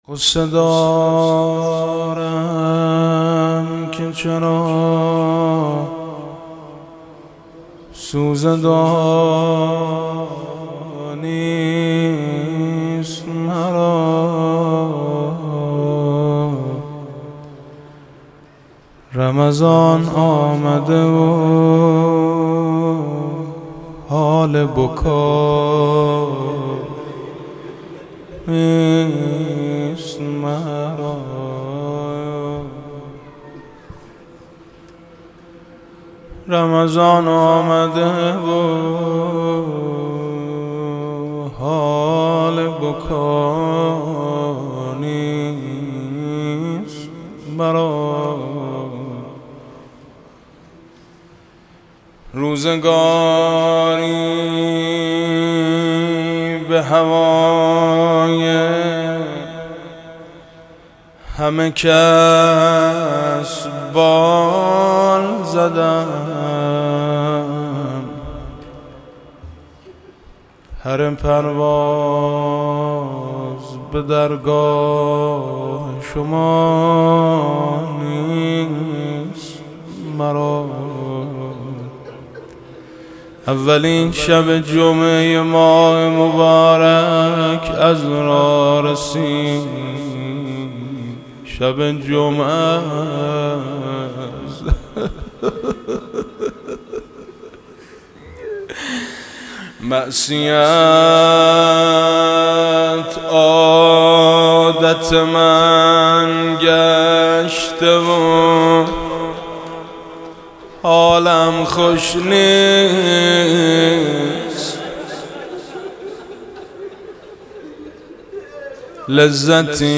معصیت عادت من گشته و حالم خوش نیست (مناجات با خدا)